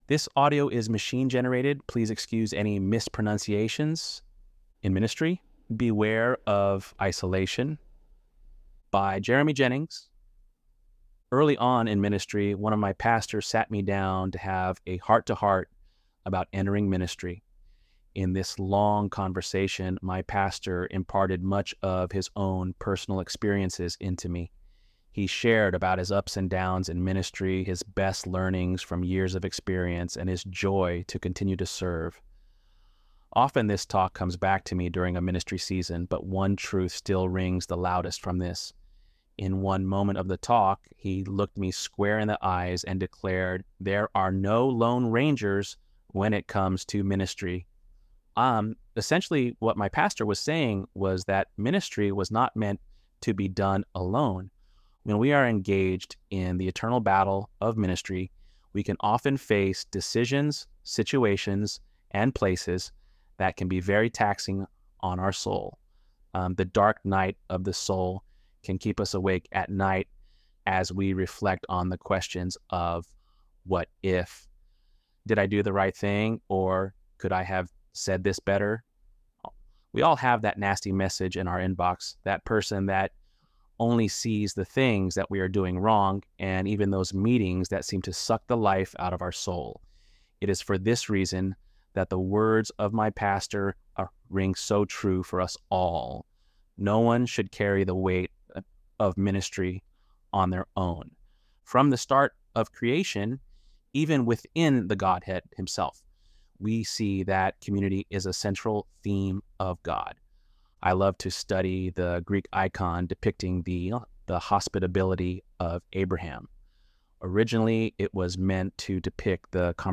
ElevenLabs_8.16.mp3